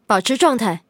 LT-35补给语音.OGG